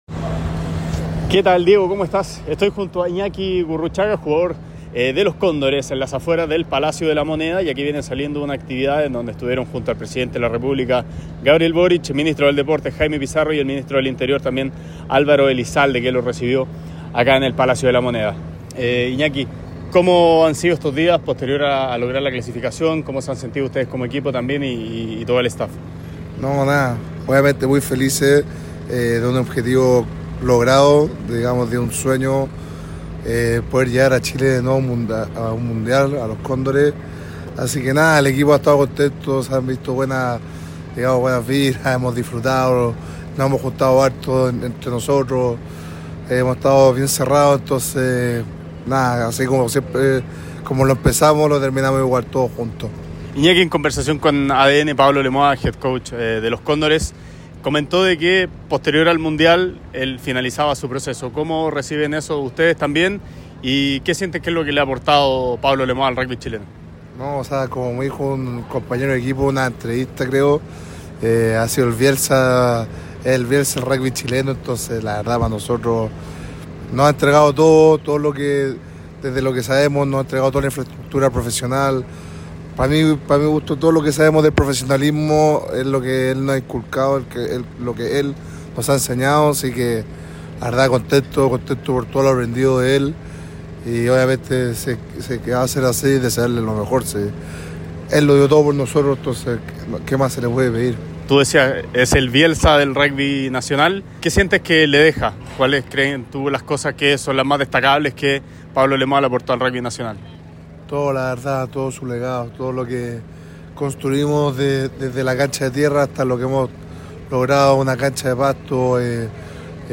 jugador de Los Cóndores